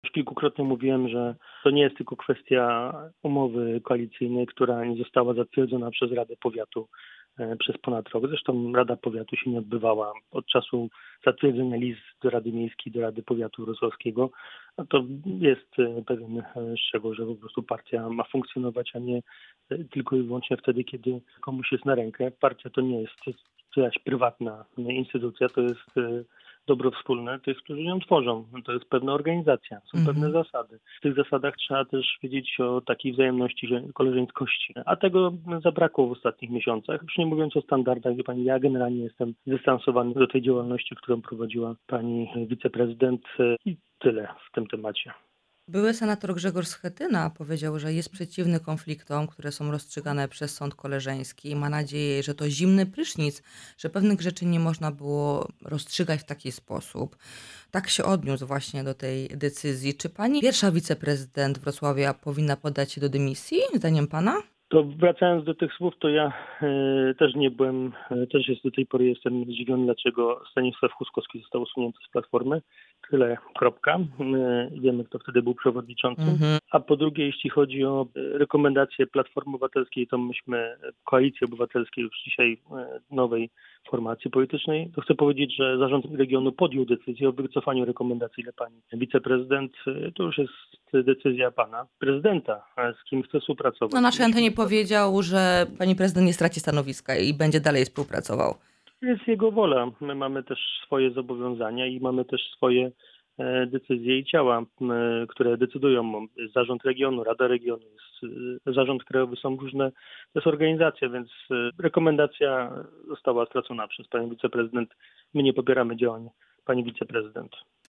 Michał Jaros – wiceminister rozwoju i technologii, szef dolnośląskich struktur KO był dziś naszym „Porannym Gościem”.